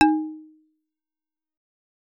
Xyl_DSharp2.L.wav